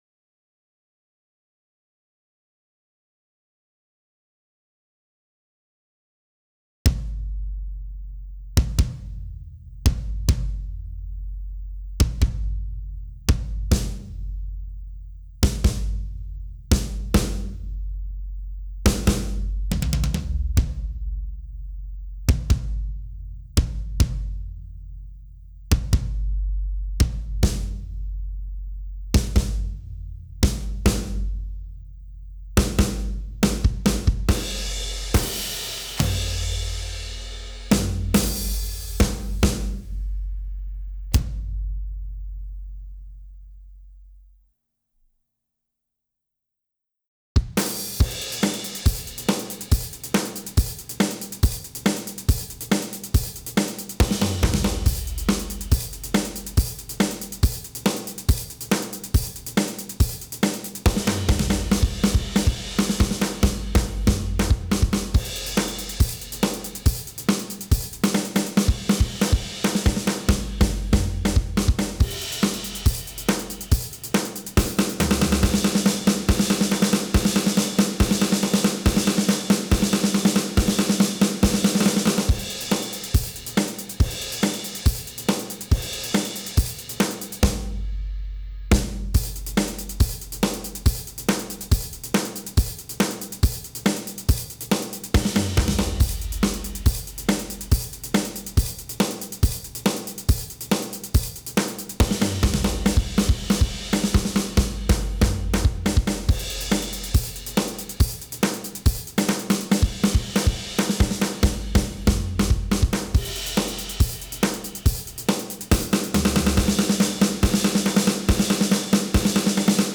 Ys Drums 170bpm
Ys-drums-170bpm.wav